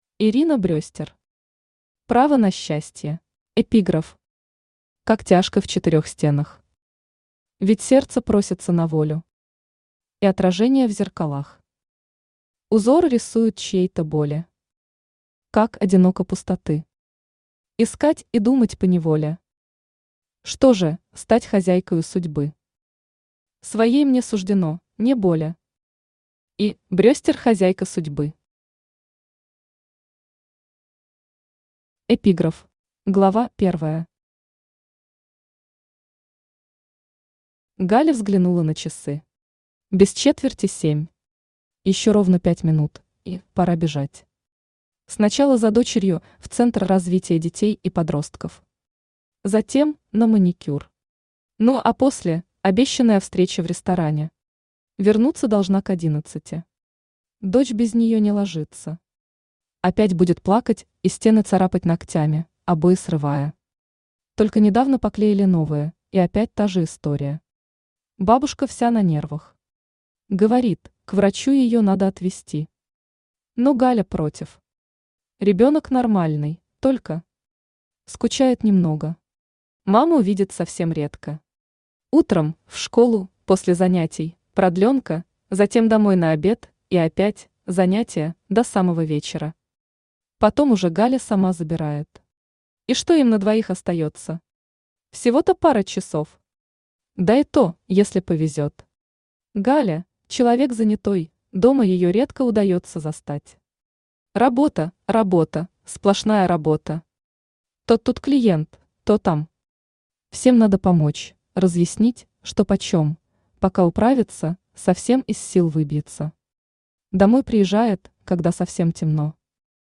Aудиокнига Право на счастье Автор Ирина Брестер Читает аудиокнигу Авточтец ЛитРес.